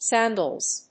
/ˈsændʌlz(米国英語)/